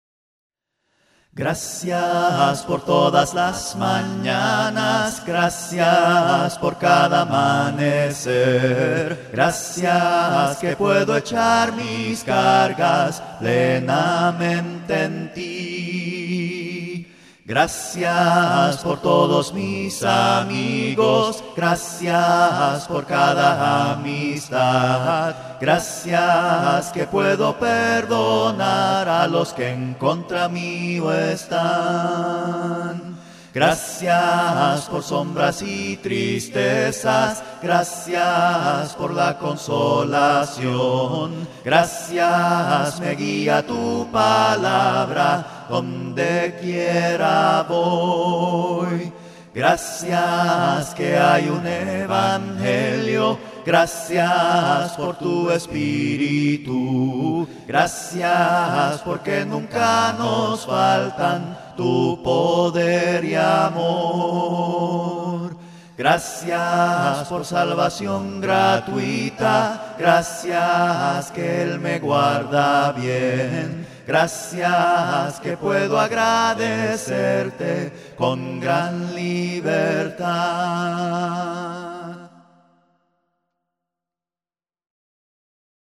Cánticos Cristianos A Cappella, Gratis
Con Múltiples Voces: